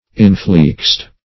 Search Result for " inflexed" : The Collaborative International Dictionary of English v.0.48: Inflexed \In*flexed"\, a. 1.